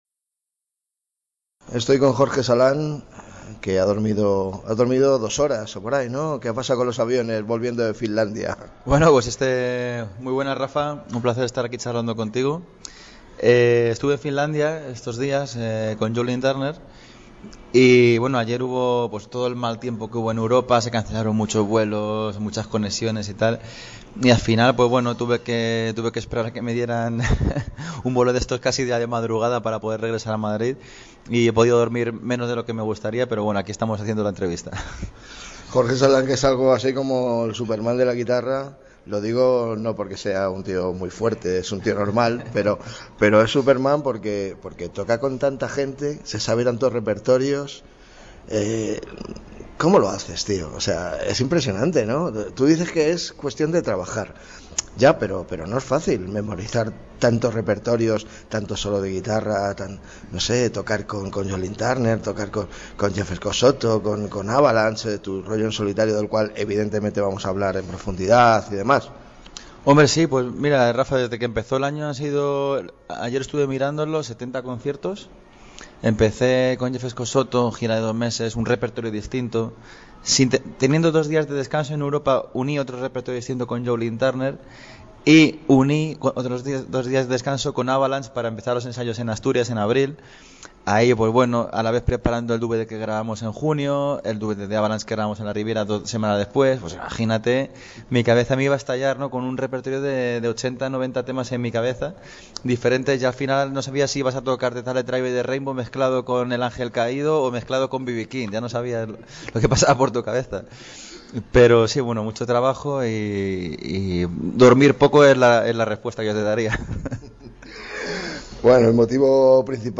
Entrevista sobre su DVD "Live In Madrid"
Esta es la entrevista.